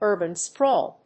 音節ùrban spráwl